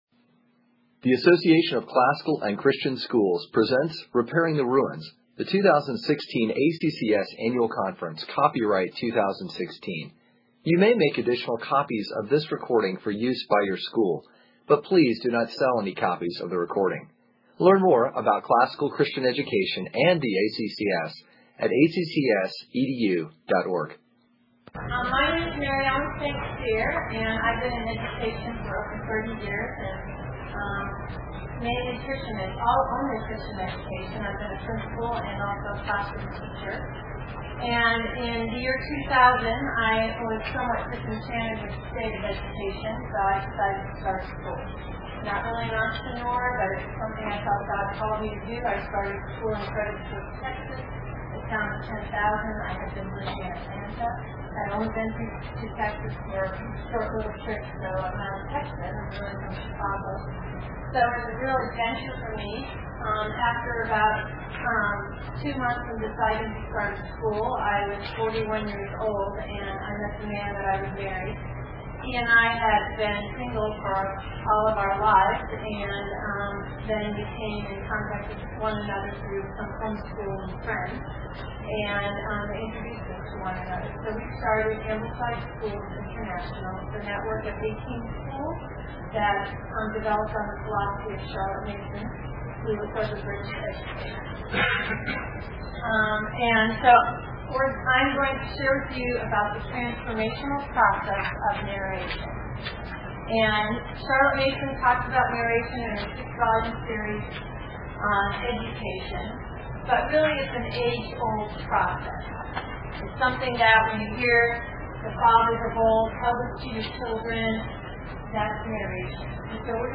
2016 Workshop Talk | 1:08:28 | Literature, Science